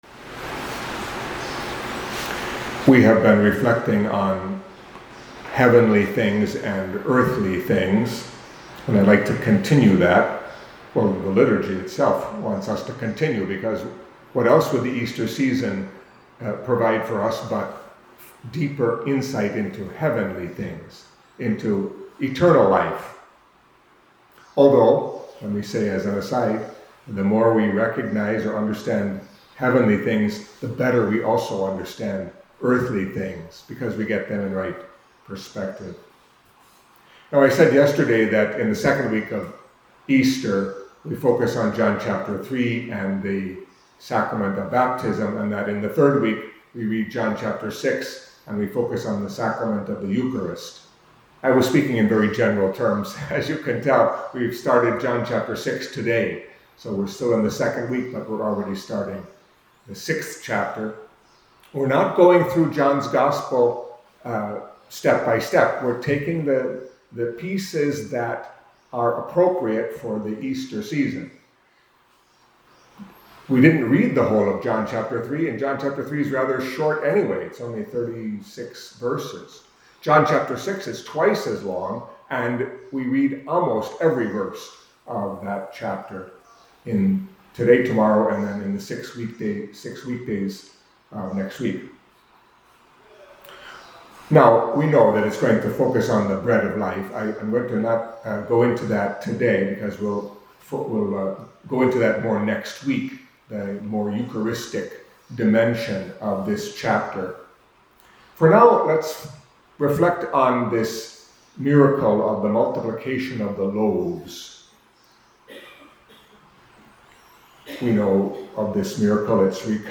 Catholic Mass homily for Friday of the 2nd Week of Easter